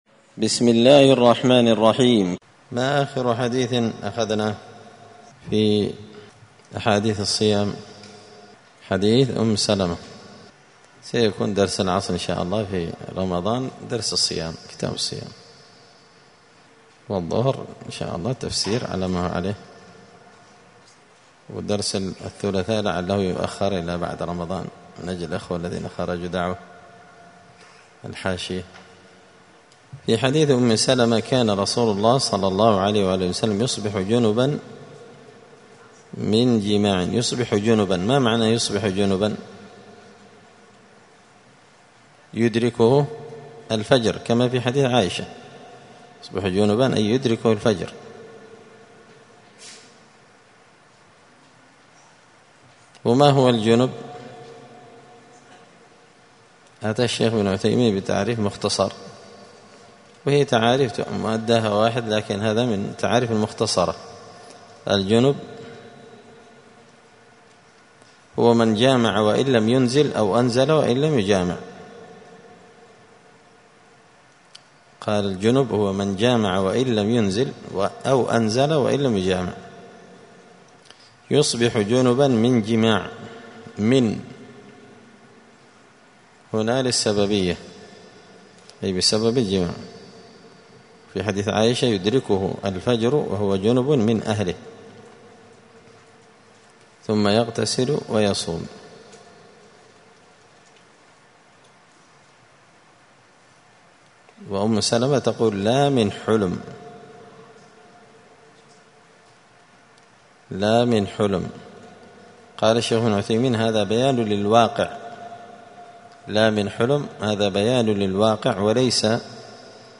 دار الحديث السلفية بمسجد الفرقان بقشن المهرة اليمن
*الدرس الرابع عشر (14) {حكم صوم المسافر…}*
14الدرس-الرابع-عشر-من-كتاب-بغية-الحافظين-كتاب-الصيام.mp3